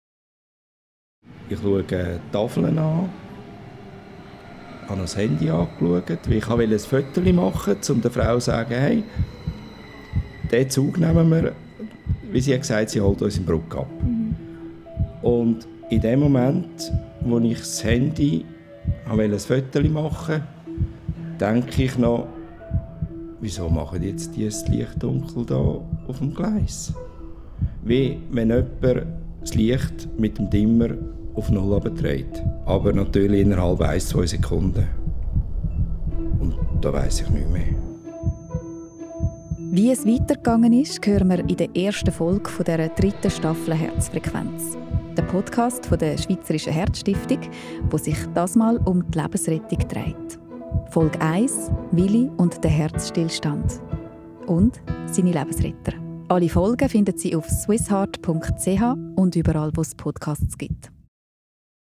Doku-Podcast
Mundart